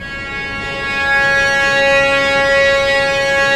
Index of /90_sSampleCDs/Roland LCDP08 Symphony Orchestra/STR_Vcs Bow FX/STR_Vcs Sul Pont